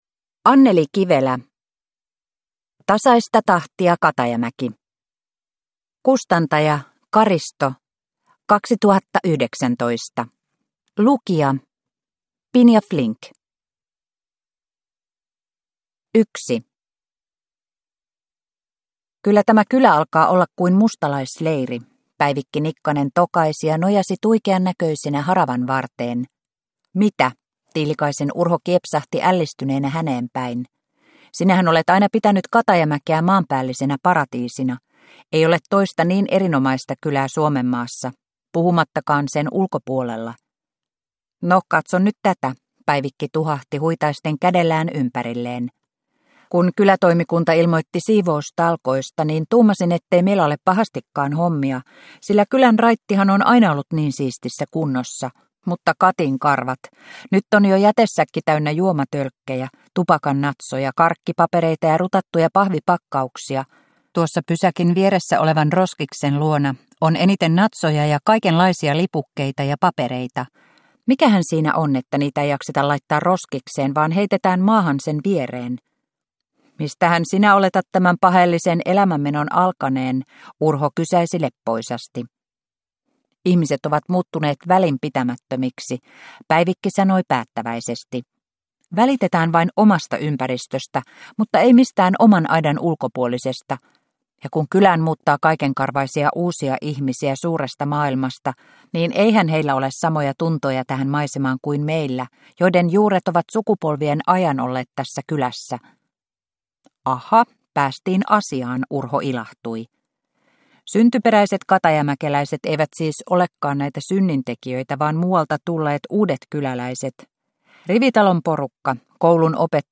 Tasaista tahtia, Katajamäki – Ljudbok – Laddas ner